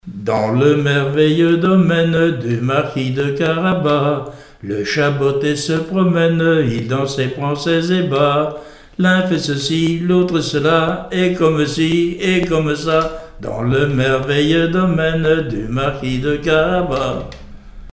chanté pour entrer en classe
Genre brève
Témoignages et musiques
Pièce musicale inédite